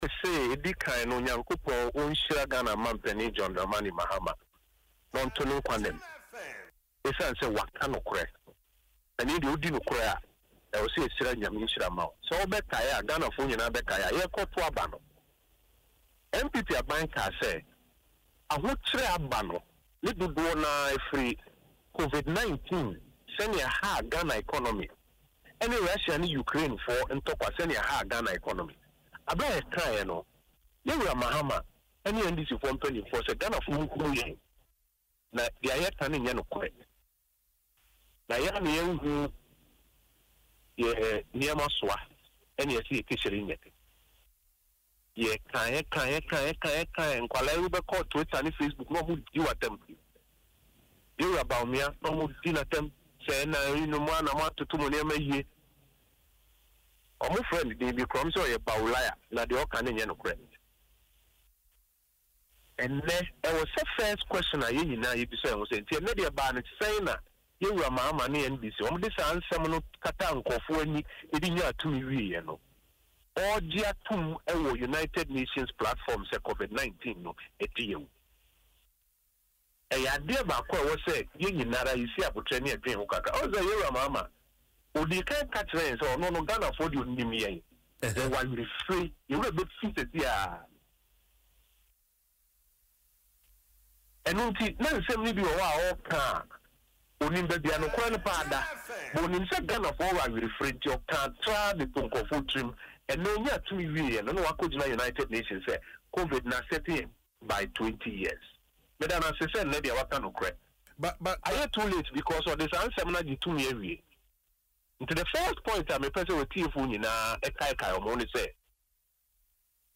Speaking in an interview on Adom FM’s Dwaso Nsem, Mr. Oppong Nkrumah recalled that ahead of the 2024 general elections, the National Democratic Congress (NDC), then in opposition, dismissed claims by the New Patriotic Party (NPP) that global shocks—including the pandemic and the Russia-Ukraine war—had contributed significantly to Ghana’s economic challenges.